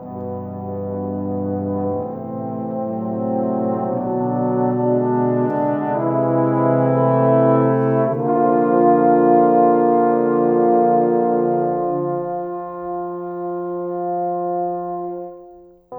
Cinematic 27 Horns 05.wav